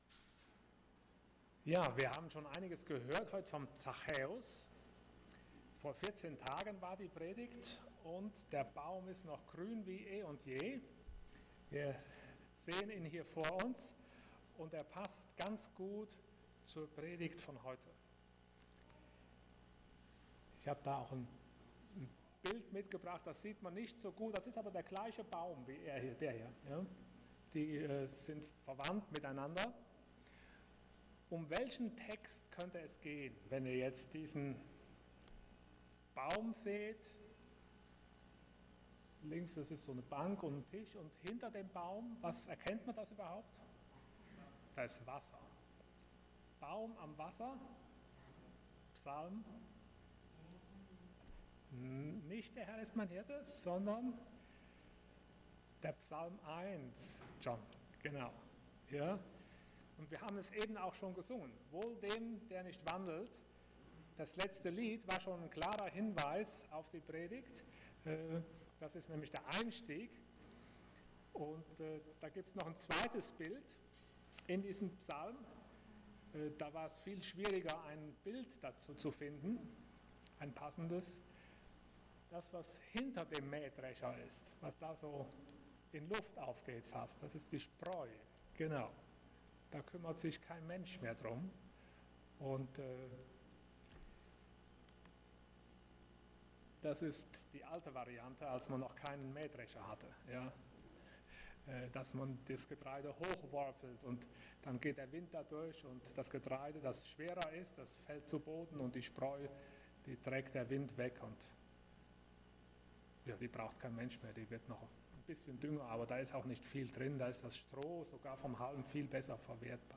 Allgemeine Predigten Passage: Psalm 1:1-6 Dienstart: Sonntag Morgen %todo_render% Damit dein Leben gelingt « Gebet Wie bekomme ich ewiges Leben?